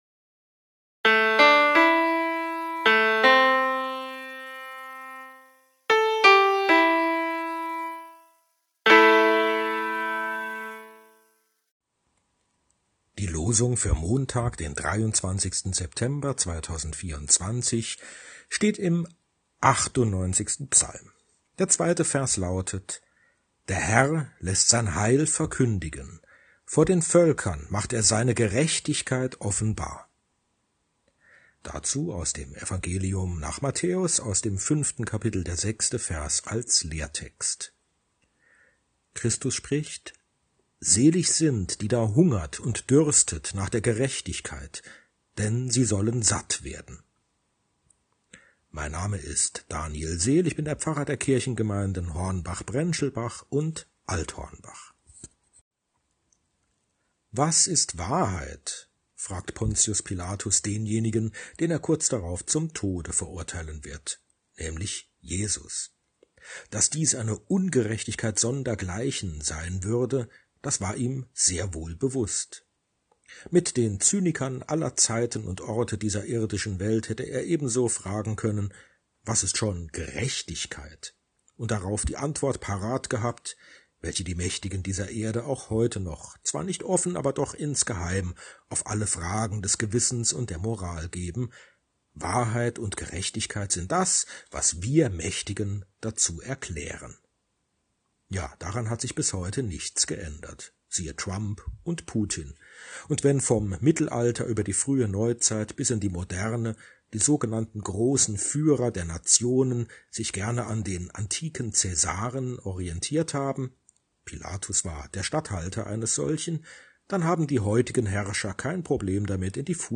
Losungsandacht für Montag, 23.09.2024